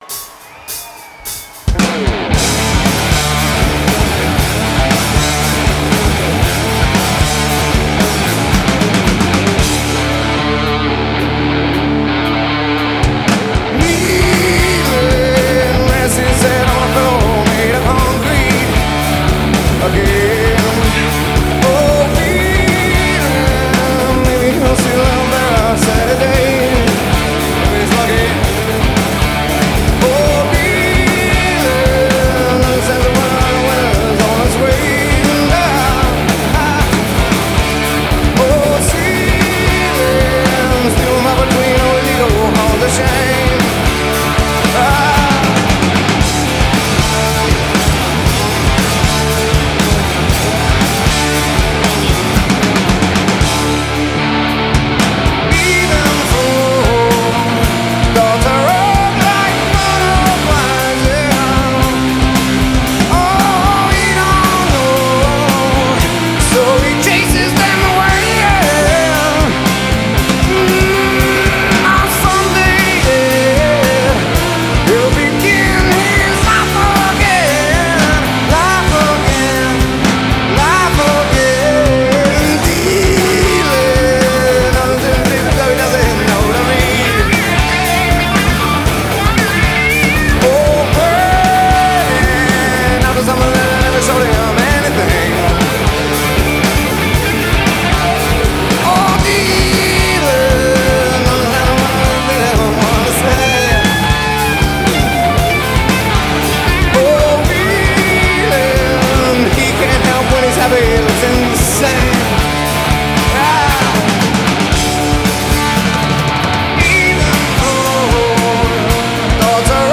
From The Forum in Inglewood, CA   7/13/1998
bootleg version